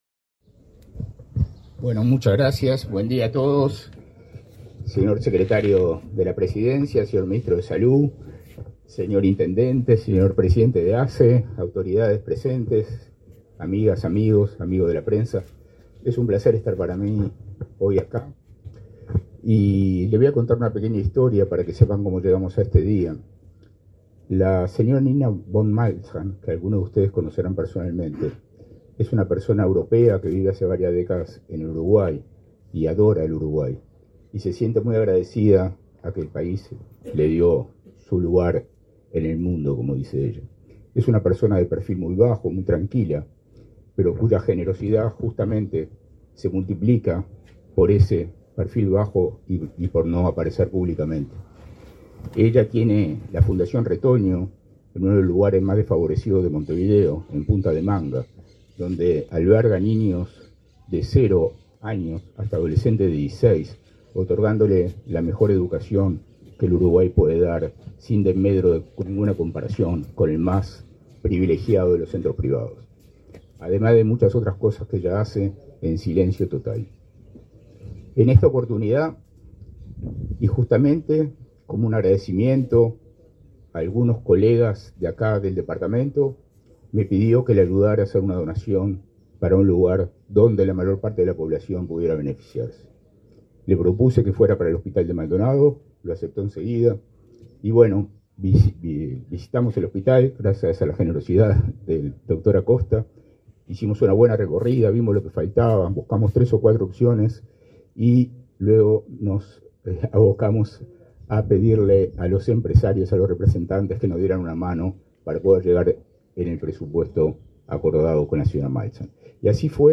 Conferencia de prensa por la inauguración de obras en el hospital de Maldonado